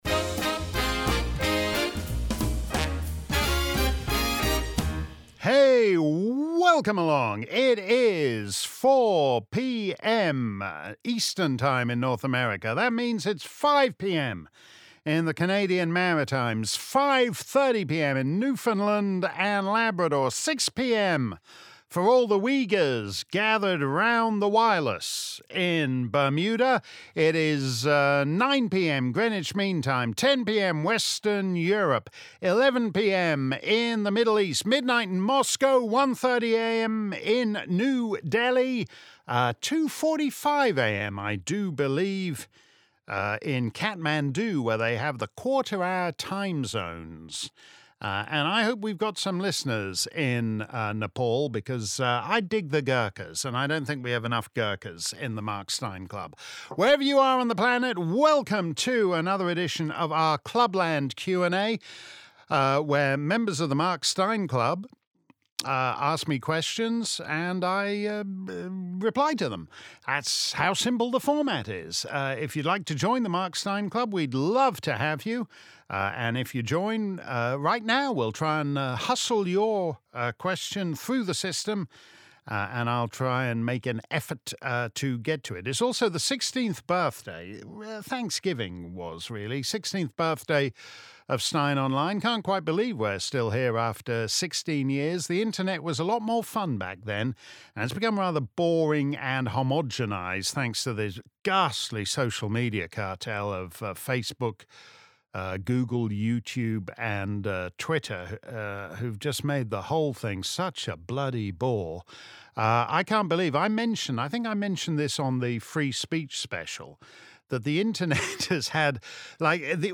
Meanwhile, if you missed our livestream Clubland Q&A, here's the action replay.